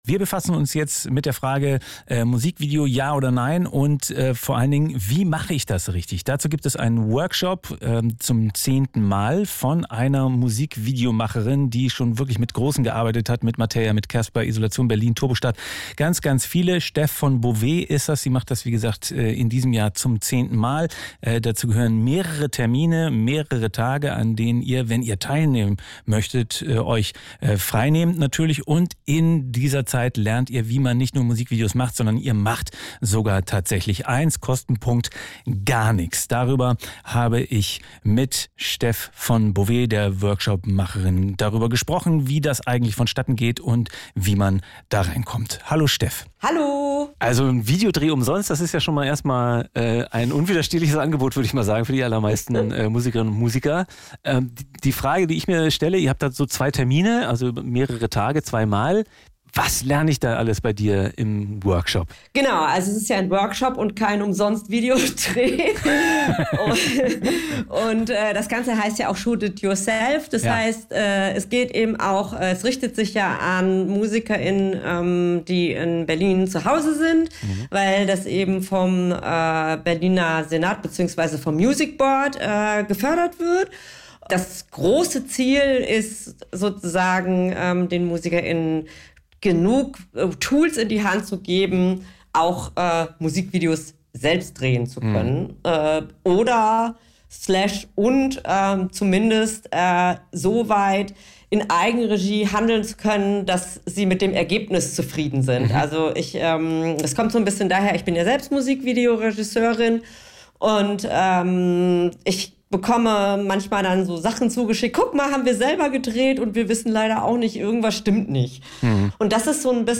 fritz_unsigned27.4._interviewSvB.mp3